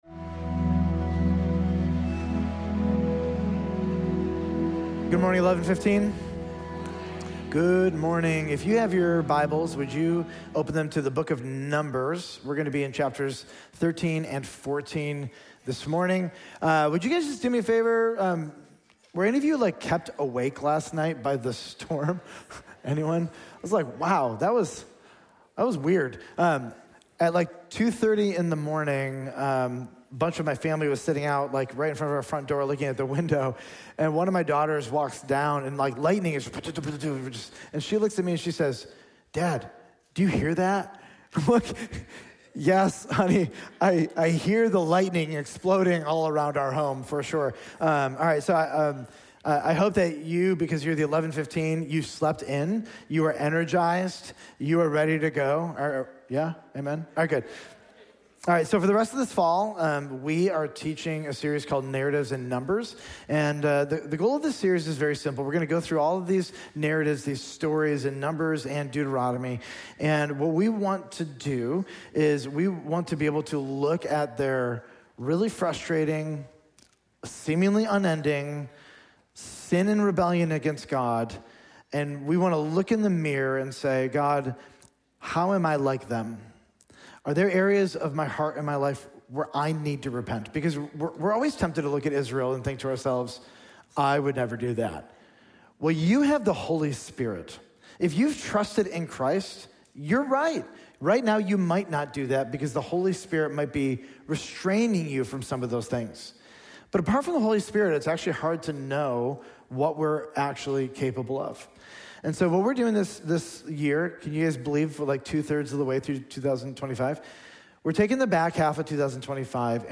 Sermons Narratives In Numbers Pt. 4